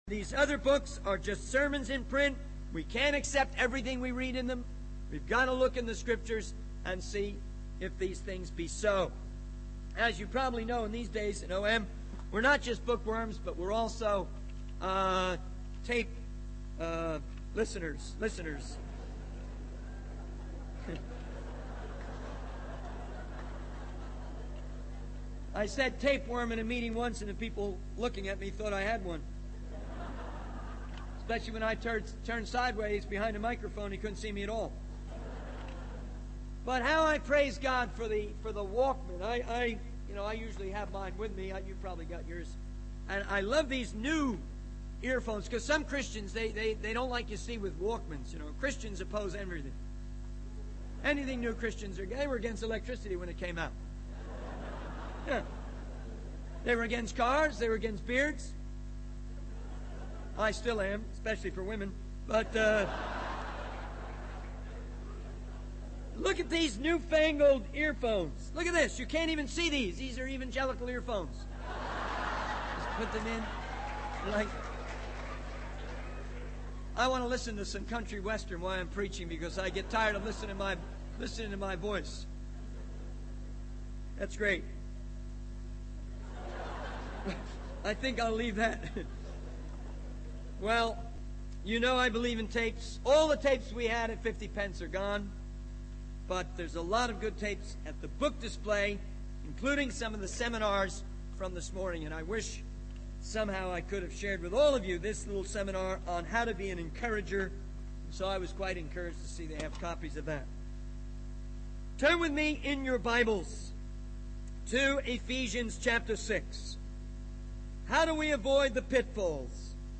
He emphasizes the importance of being strong in the Lord and putting on the whole armor of God to stand against the devil's schemes. The speaker encourages the audience to mark their Bibles and highlights the power of forgiveness, urging Christians to practice it even in the face of adversity. The video concludes with a reminder that Jesus Christ is Lord and invites the audience to personally declare Him as their Lord.